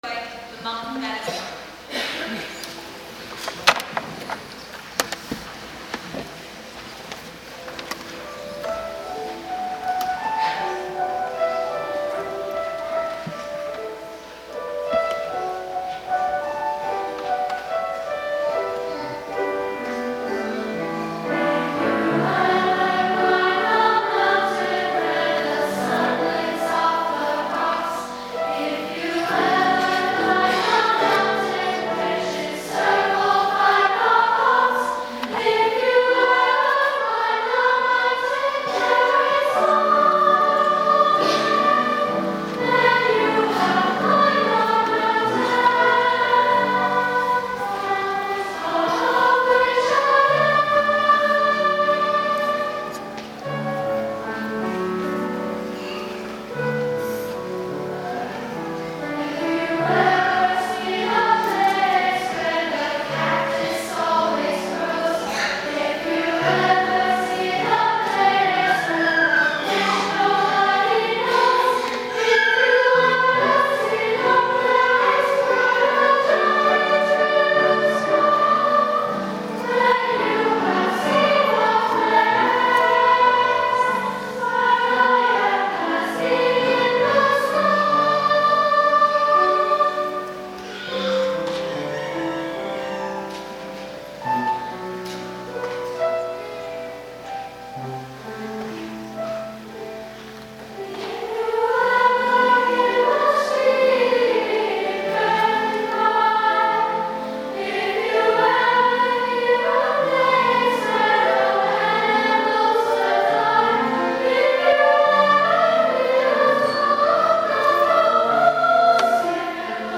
Solo or unison voices & piano